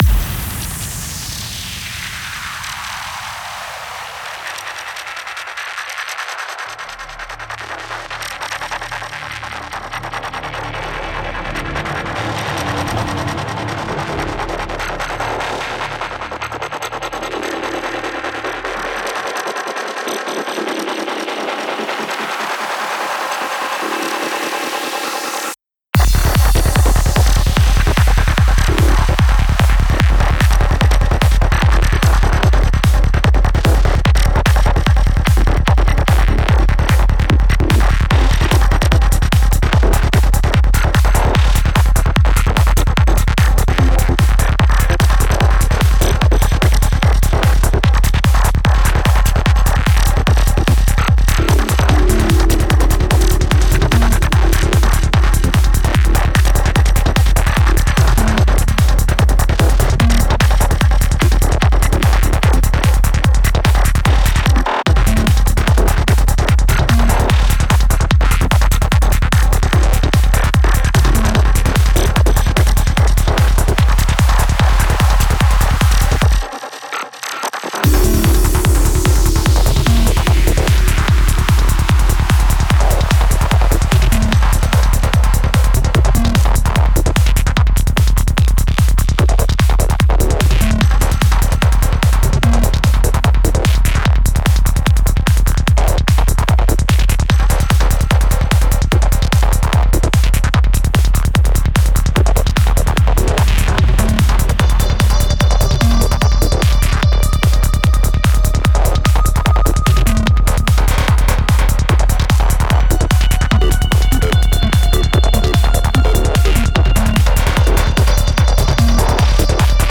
Genre: House.